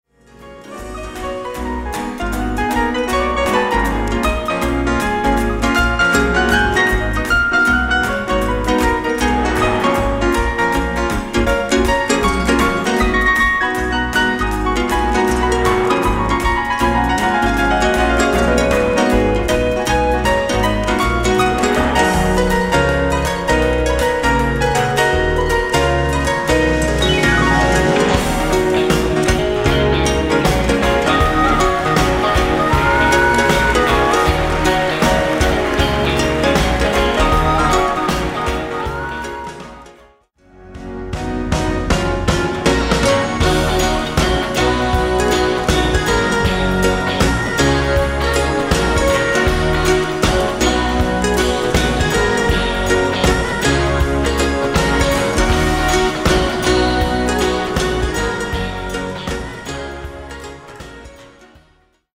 Live recordings from:
harps, gu-cheng, ocarina, vocals